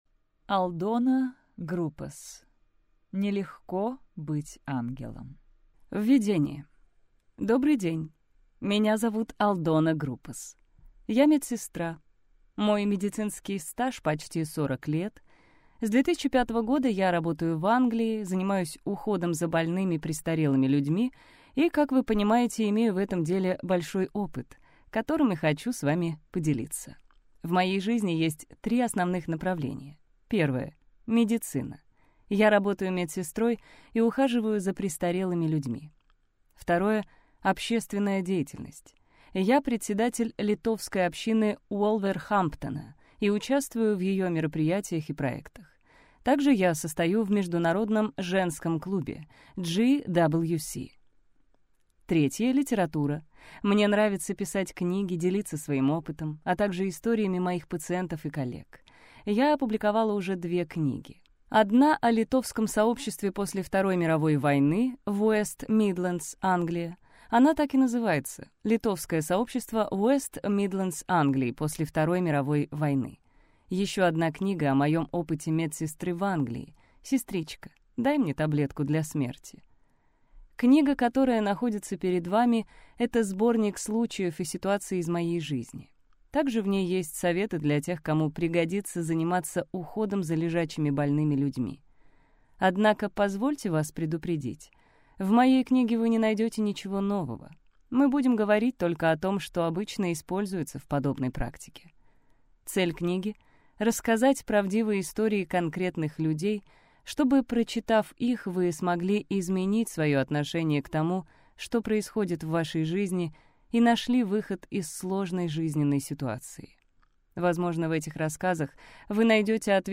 Аудиокнига Нелегко быть ангелом | Библиотека аудиокниг